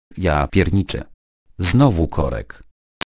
Dźwięki ostrzegawcze Korki i Zatory Drogowe
Dźwięki ostrzegawcze Korki i Zatory Drogowe Pobierz gotowe komunikaty głosowe / alarmy ostrzegawcze w formacie MP3.
korki-i-zatory-drogowe-41